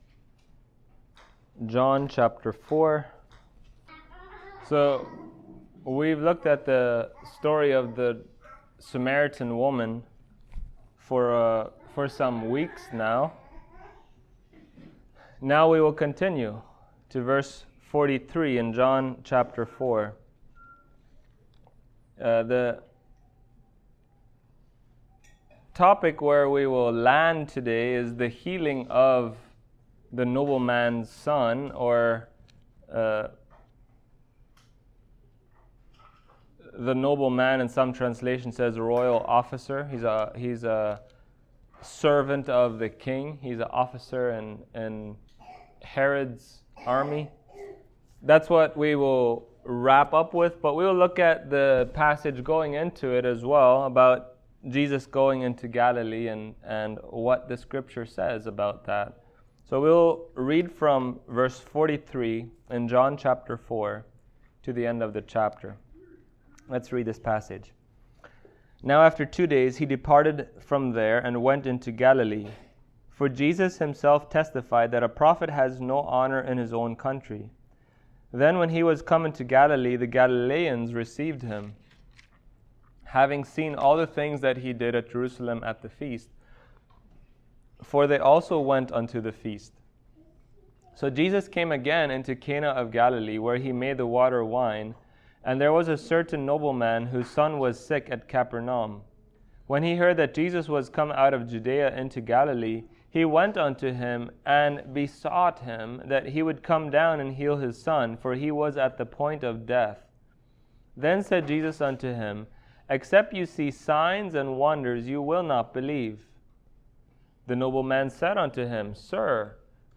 Passage: John 4:43-54 Service Type: Sunday Morning